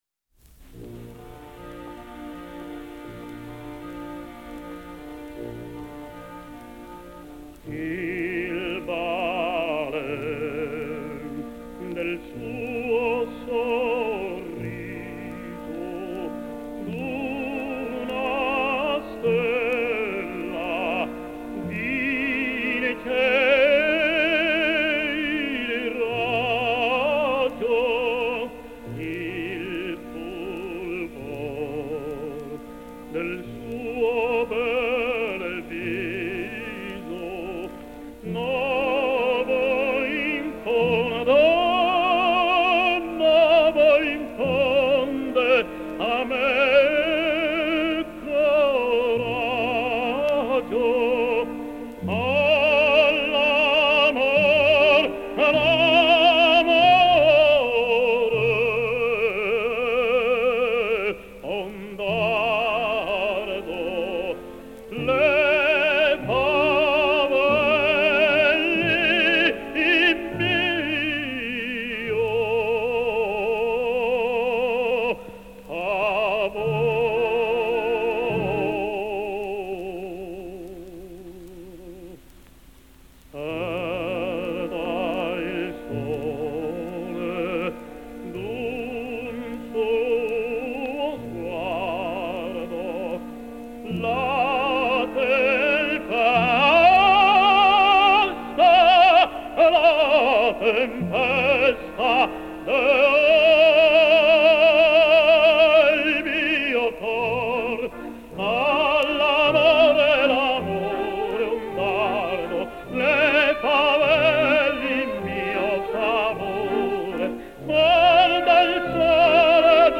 И еще один день рождения - выдающегося итальянского баритона Джузеппе Де Лука (1876-1950)!!!